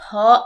/ por